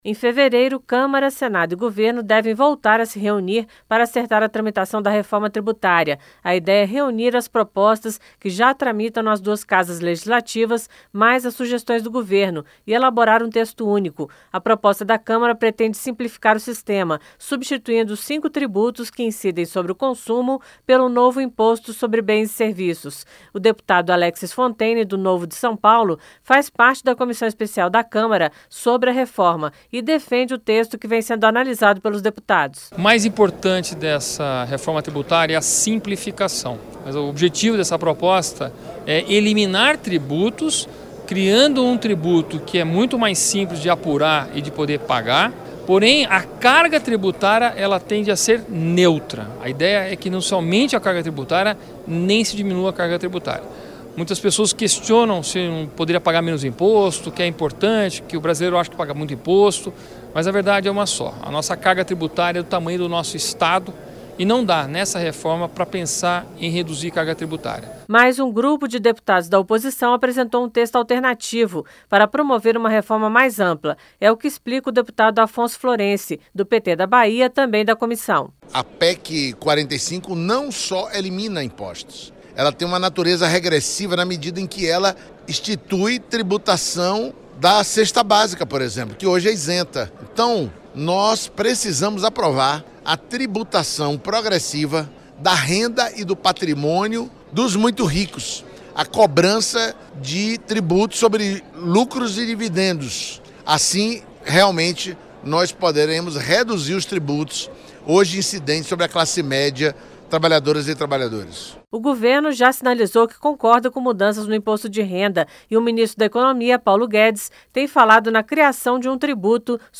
O deputado Alexis Fonteyne (Novo-SP) faz parte da comissão especial sobre a reforma e defende o texto que vem sendo analisado pelos deputados: